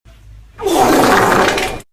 shit post fart
shit-post-fart.mp3